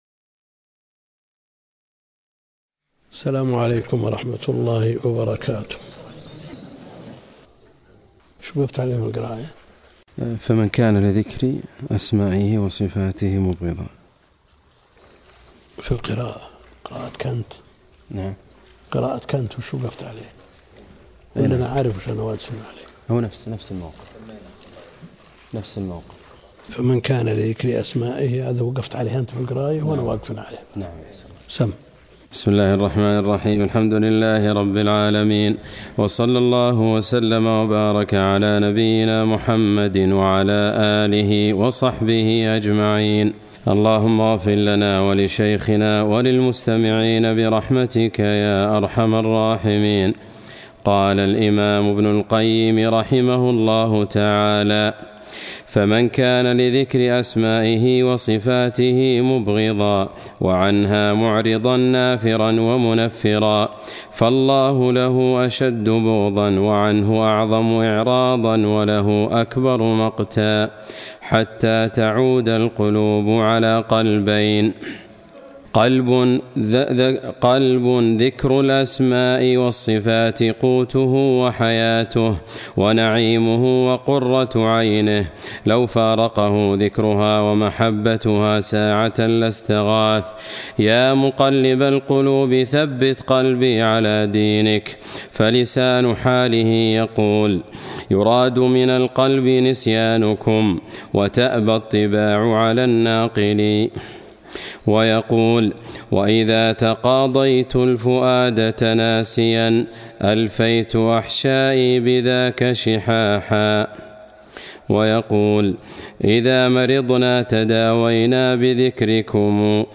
الدرس (7) شرح نونية ابن القيم - الدكتور عبد الكريم الخضير